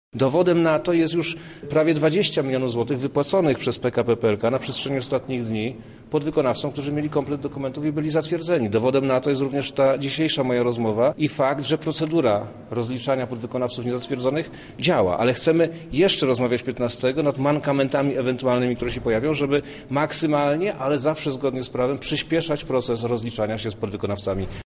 Żaden podwykonawca nie zostanie bez zapłaty za wykonaną usługę – mówi Wojewoda Lubelski Przemysław Czarnek: